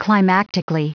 Prononciation du mot : climactically
climactically.wav